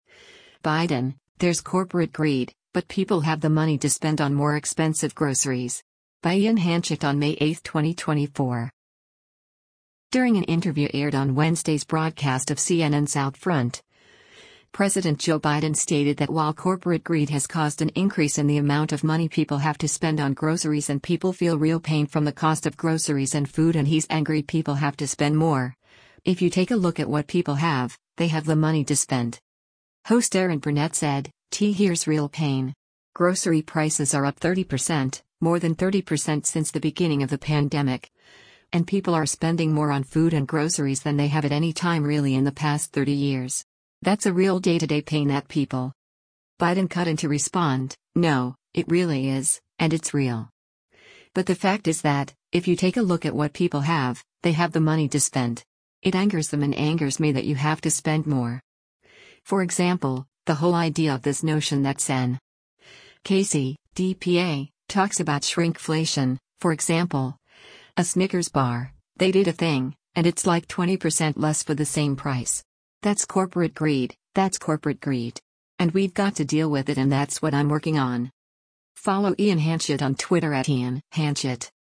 During an interview aired on Wednesday’s broadcast of CNN’s “OutFront,” President Joe Biden stated that while “corporate greed” has caused an increase in the amount of money people have to spend on groceries and people feel real pain from the cost of groceries and food and he’s angry people have to spend more, “if you take a look at what people have, they have the money to spend.”